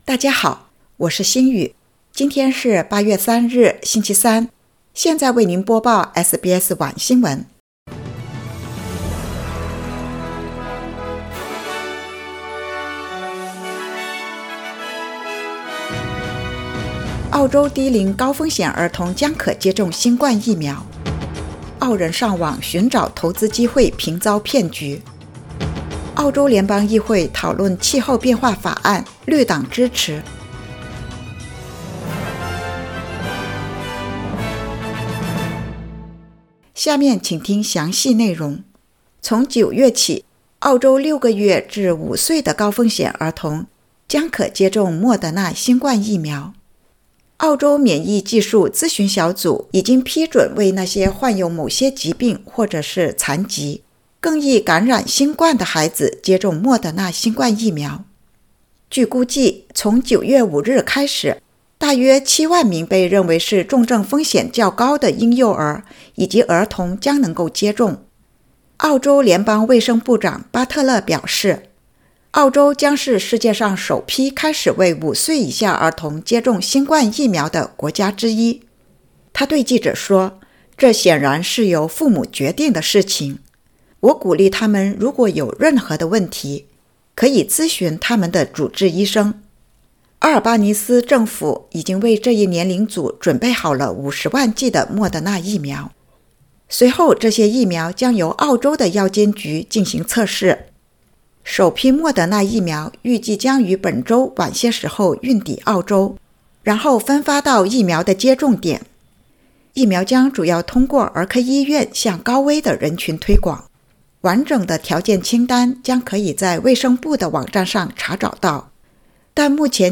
SBS晚新闻（2022年8月3日）